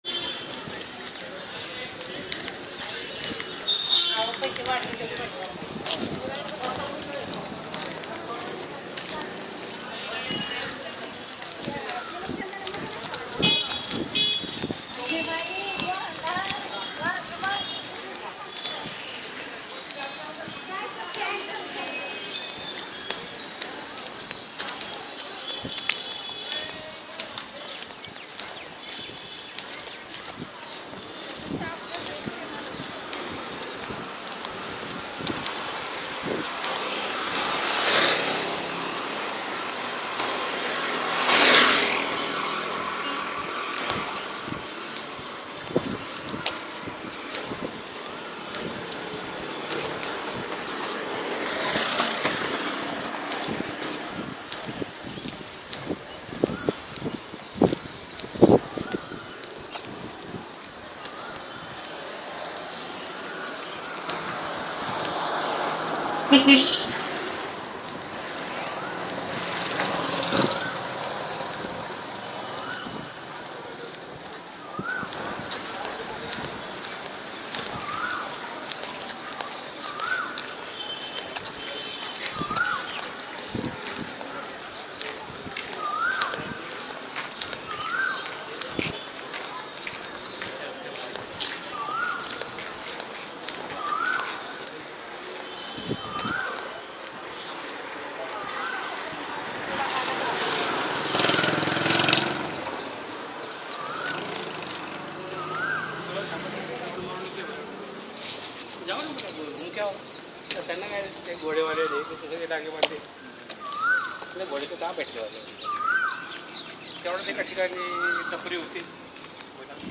布鲁克林下了些雨
描述：从我的窗台上录制的一场温和的夏季风暴。雨滴，风，遥远的交通＆amp;警笛，轻柔的雷声
标签： 音景 城市景观 环境 风暴 天气 环境 自然 大气 城市
声道立体声